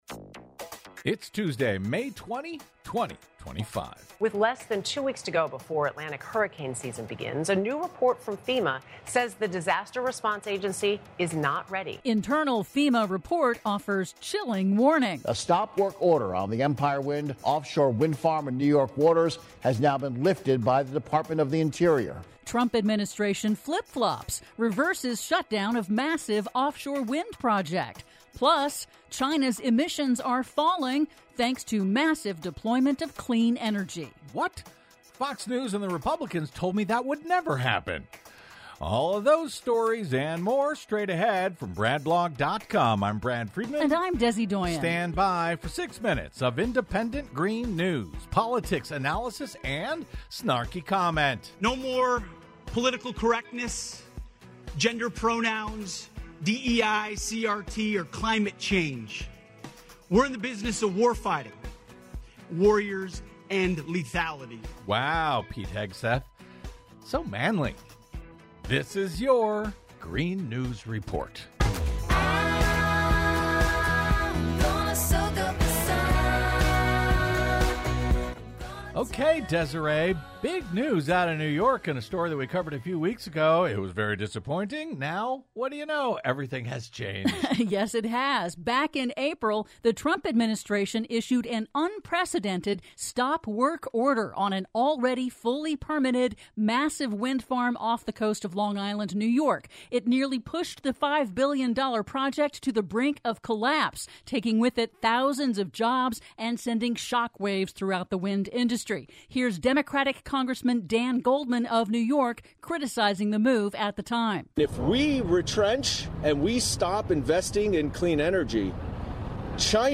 IN TODAY'S RADIO REPORT: Internal FEMA report warns agency is not ready for hurricane season; Trump administration flip-flops, reverses shutdown of massive offshore wind project; PLUS: China's emissions are falling, thanks to massive deployment of clean energy... All that and more in today's Green News Report!